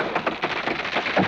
Index of /90_sSampleCDs/E-MU Producer Series Vol. 3 – Hollywood Sound Effects/Water/Falling Branches
LIMB CRAC05R.wav